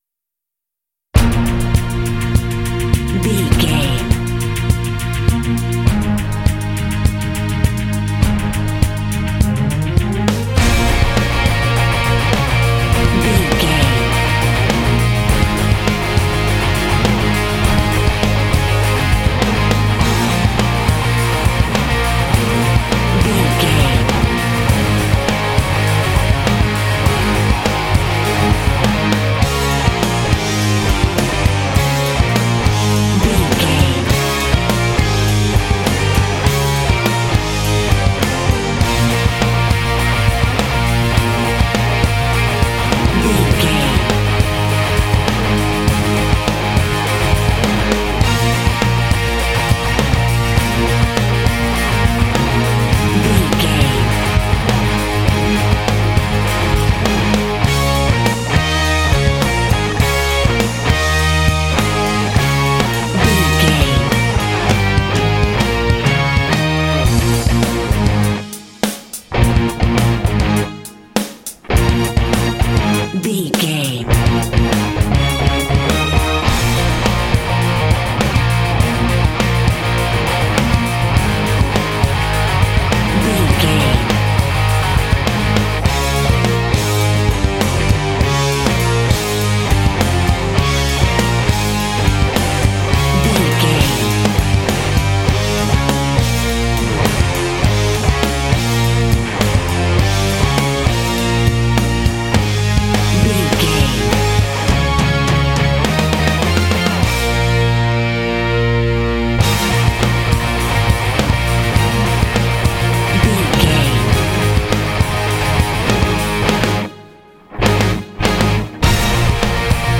Epic / Action
Aeolian/Minor
B♭
powerful
energetic
heavy
strings
bass guitar
electric guitar
drums
heavy metal
symphonic rock